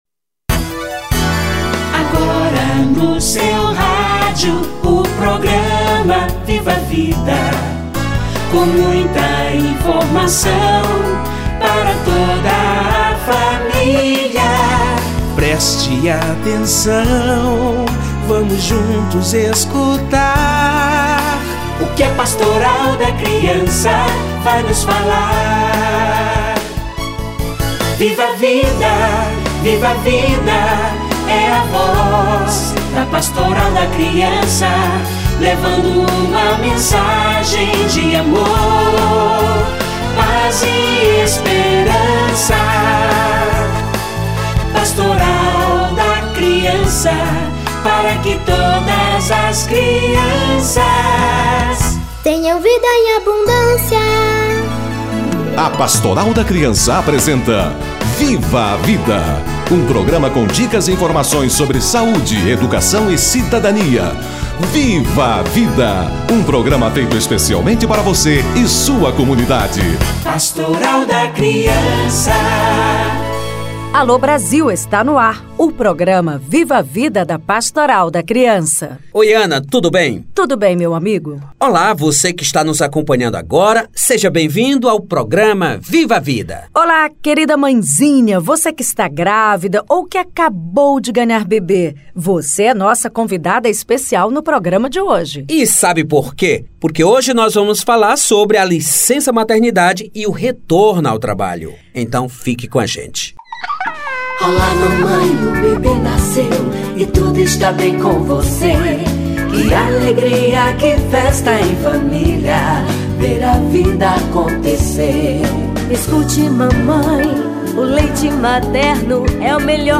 Gestante trabalhadora - Entrevista